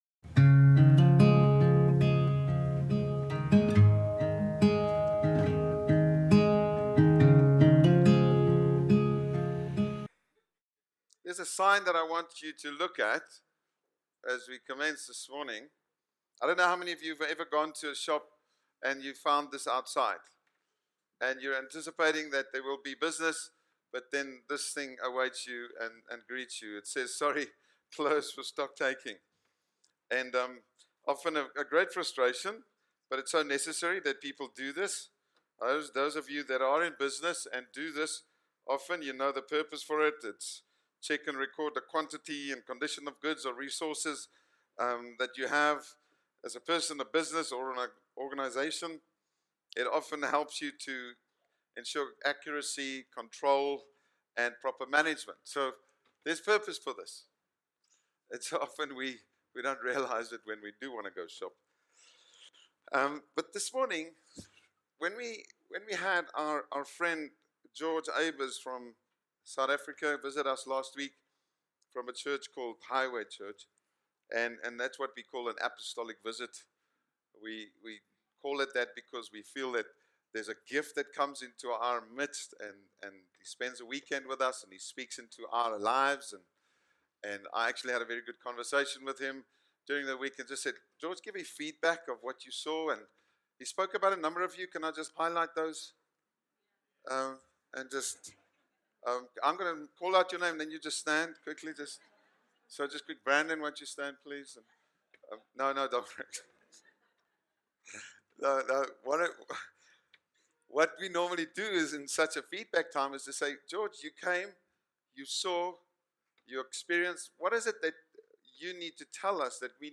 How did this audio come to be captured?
Church in Bulawayo